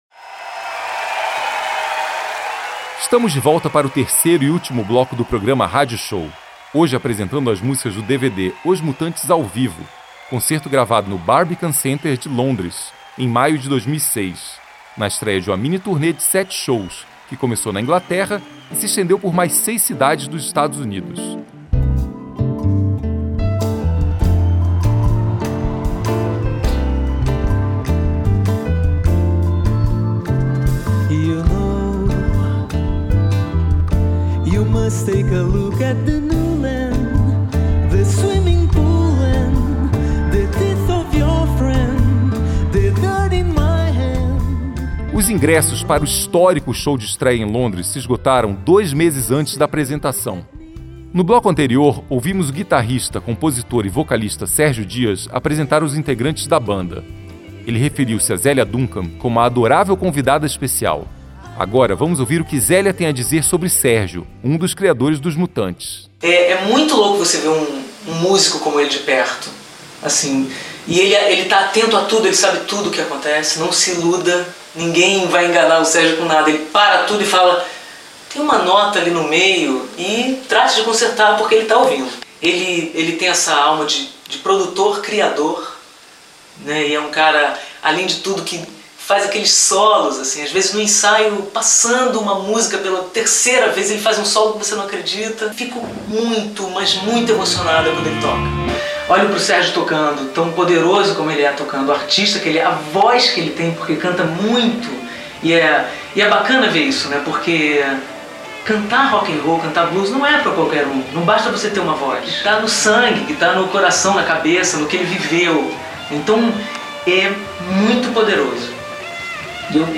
Rock Pop Pop rock